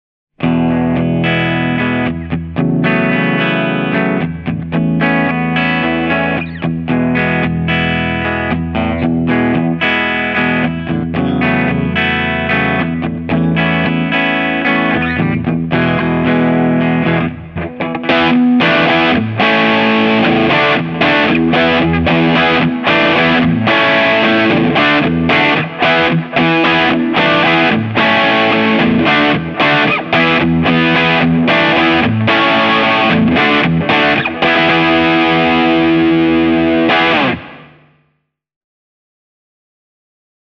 Here are three soundbites I recorded for you of three different P-90 bridge pickups on three different guitars:
• the Vintage VS6 Mick Abrahams has Wilkinson soapbars with plastic covers
All guitars were played through a clean Fender-type amp model with a Boss SD-1 in front.
VS6 Mick Abrahams bridge P-90
vintage-mick-abrahams-bridge-p90.mp3